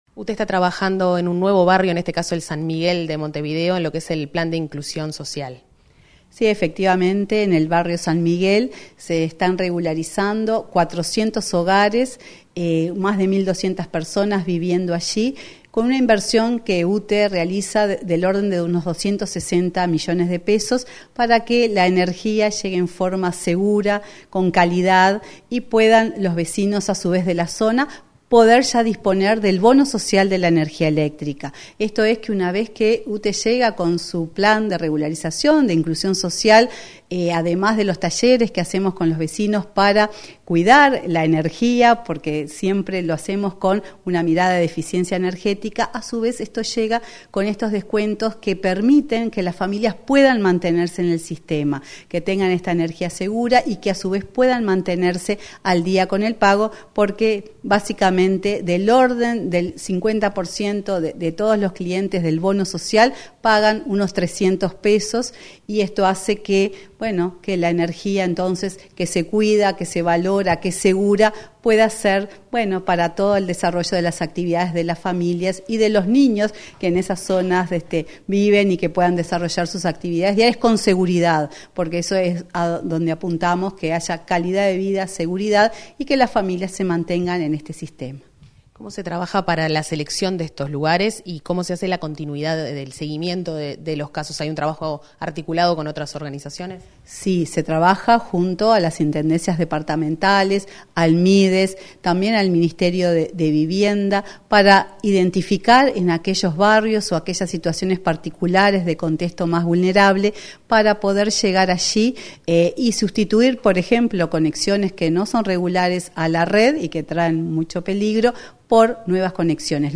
Entrevista a la presidenta de UTE, Silvia Emaldi, sobre regularización del barrio San Miguel